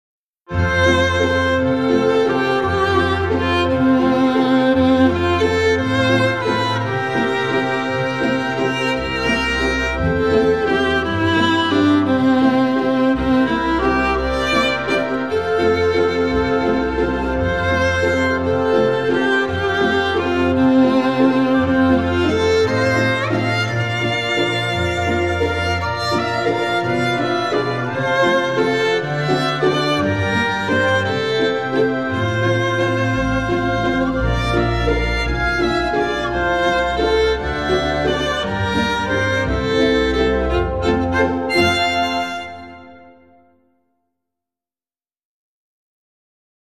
Moderato [100-110] amour - violon - valse - danse - ancien
valse - danse - ancien